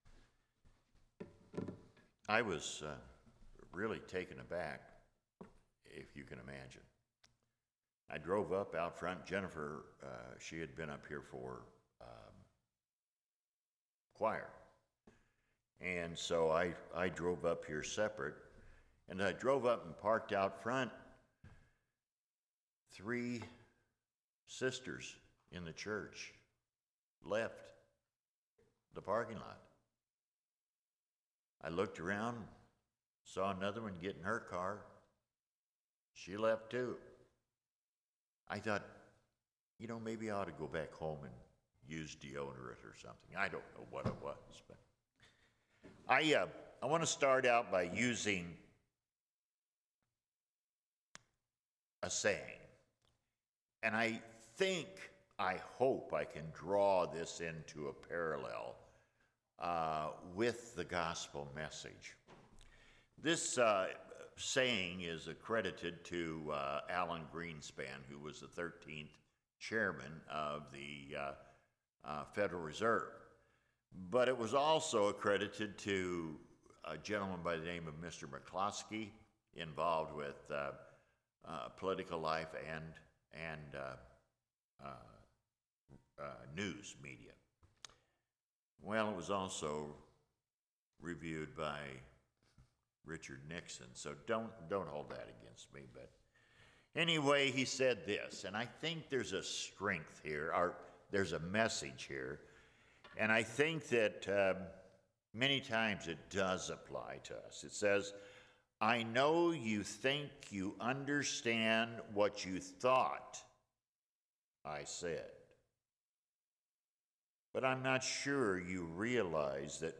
10/30/2022 Location: Temple Lot Local Event